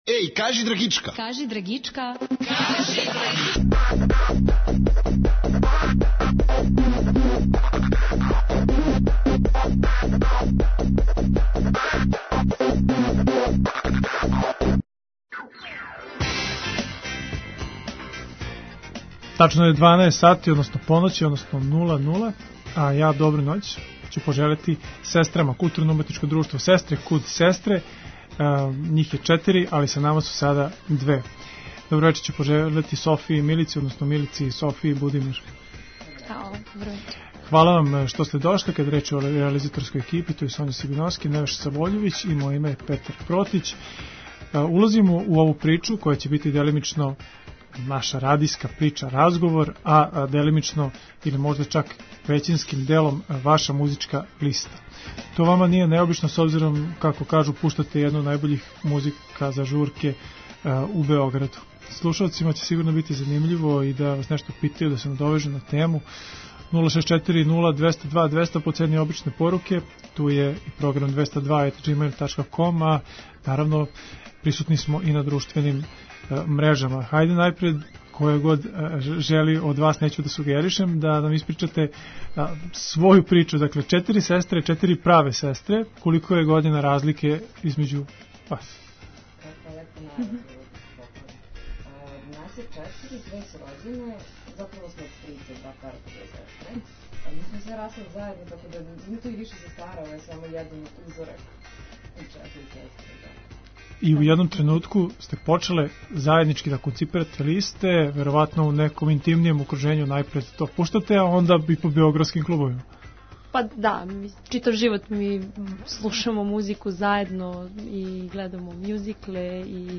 Угостићемо четири (праве) сестре које су уједно четири музичарке уз које је провод увек загарантован.
Оне вас покрећу уз гаражни рок, пост-панк, инди-поп, фанк, шлагере, њу-вејв, њу-рејв, а све то са елементима ђускавца и стискавца.